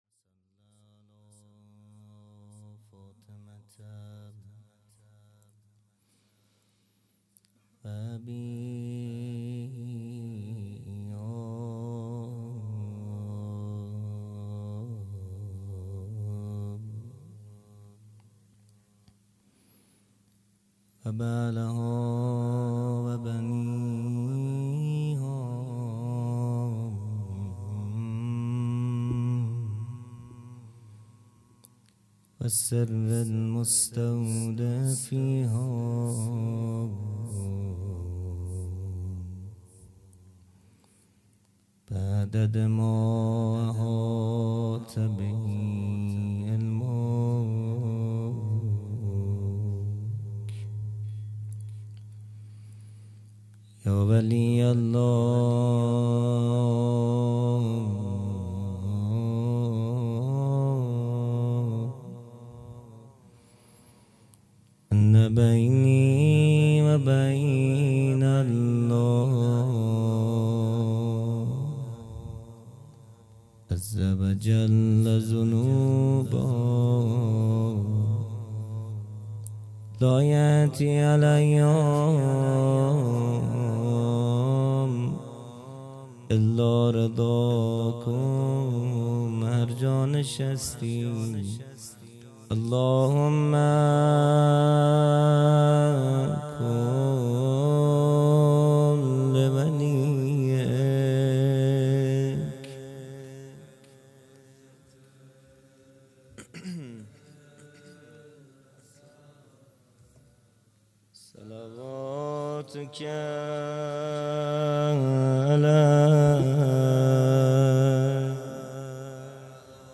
خیمه گاه - هیئت بچه های فاطمه (س) - بخش اول مناجات | پنج شنبه ۲ بهمن ۹۹
جلسۀ هفتگی